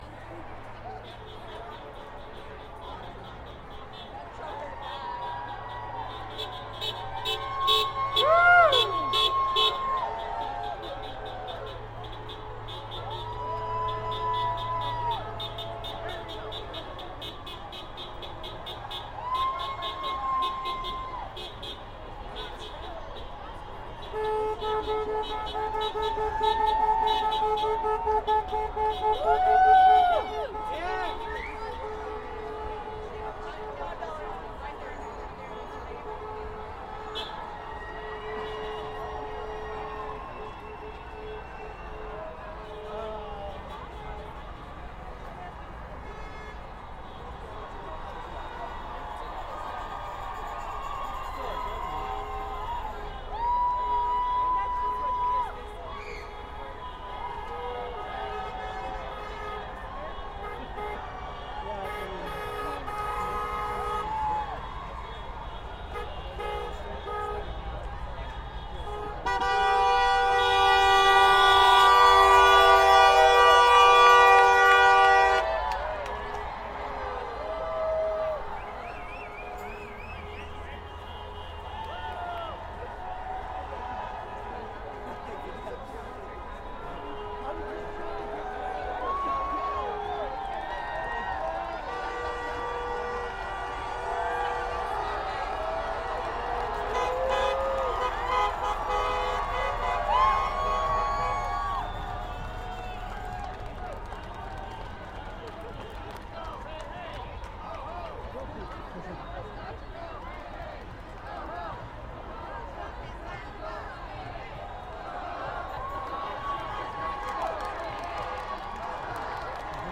21.01.17 Women's March LA - crowd cheering prompted by multiple car horns honking their support.